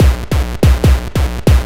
Kick 143-BPM.wav